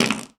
epic_bellow_04.ogg